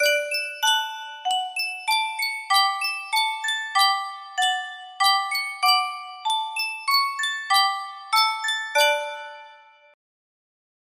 Sankyo Miniature Music Box - Igor Stravinsky The Firebird YG music box melody
Sankyo Miniature Music Box - Igor Stravinsky The Firebird YG
Full range 60